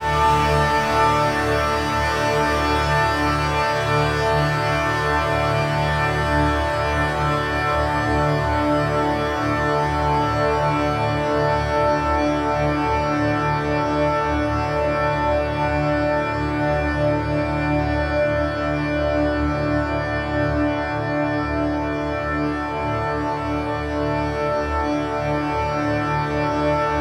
OVAT PAD.wav